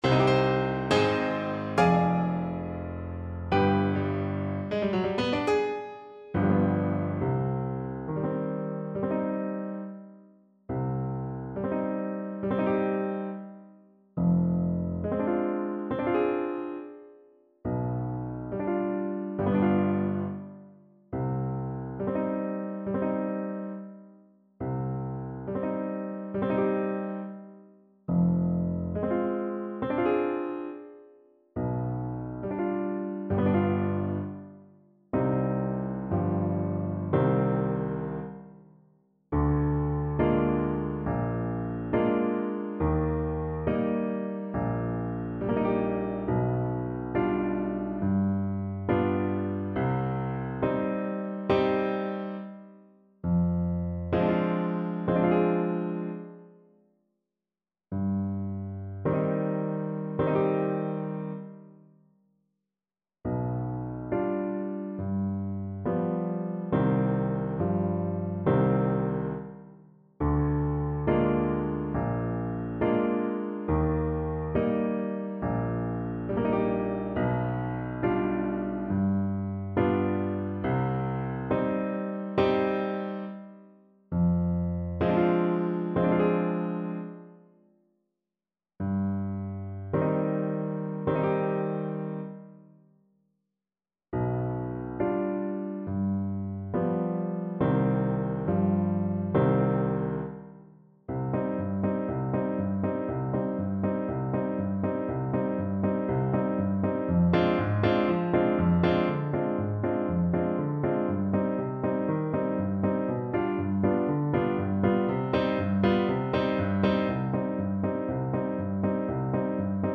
Play (or use space bar on your keyboard) Pause Music Playalong - Piano Accompaniment Playalong Band Accompaniment not yet available transpose reset tempo print settings full screen
Clarinet
C minor (Sounding Pitch) D minor (Clarinet in Bb) (View more C minor Music for Clarinet )
Largo = c.69
2/4 (View more 2/4 Music)
Classical (View more Classical Clarinet Music)
Gypsy music for clarinet